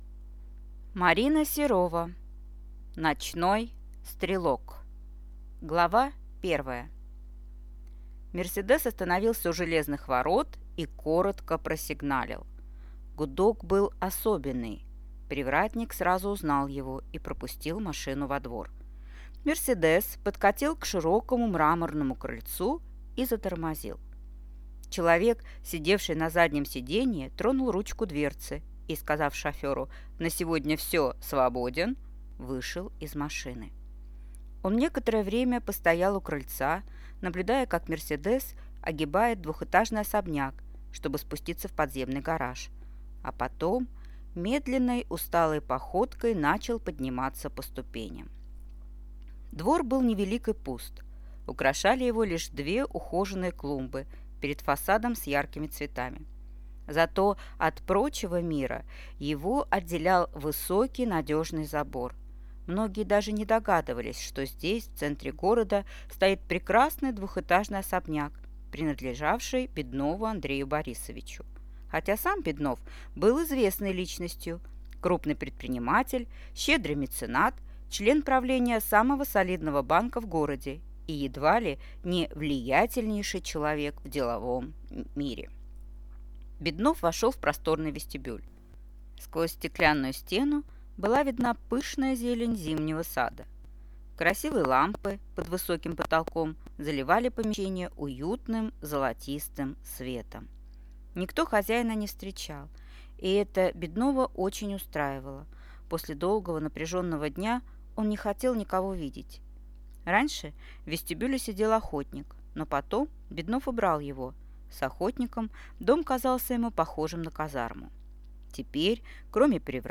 Аудиокнига Ночной стрелок | Библиотека аудиокниг